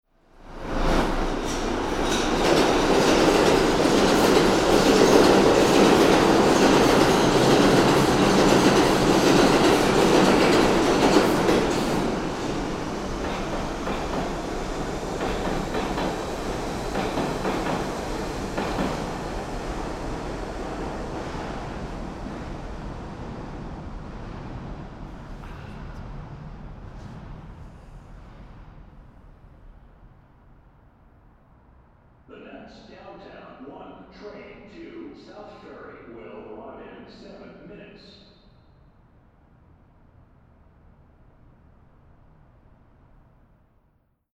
Captés avec le plus grand soin sur enregistreur Sound-Device et microphone Neuman double MS km 120-140, mastering protools 24b-48kHz ou 24b-96kHz.
waiting in the station
NY metro
FF-world-NY-Metro-amb-station-vide-pass-metro-GP-Duplict_01.mp3